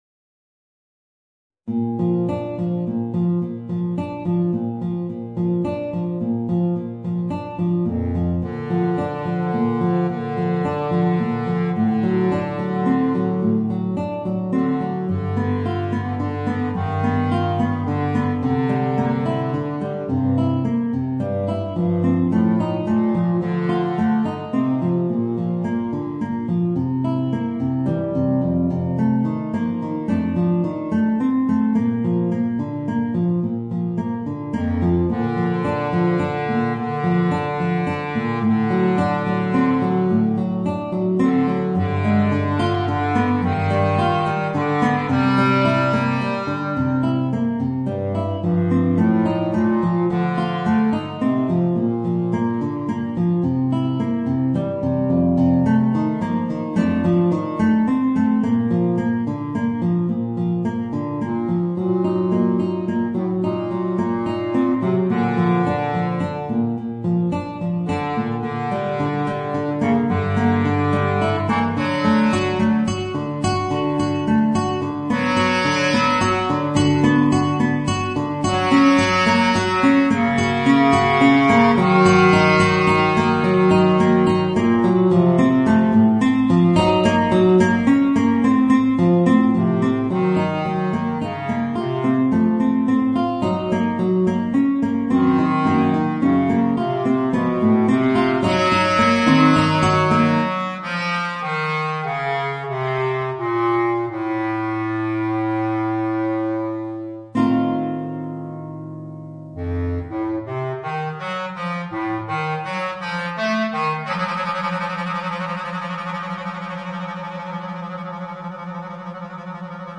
Voicing: Bass Clarinet and Guitar